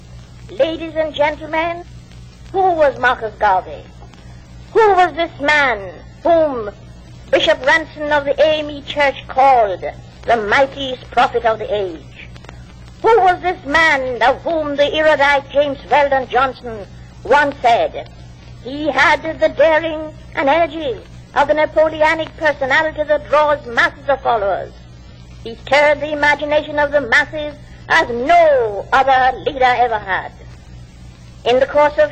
Tape of a speech made by Amy Ashwood Garvey (wife of Marcus Garvey) and religious music recorded at a memorial service in his honour.
2 audio cassettes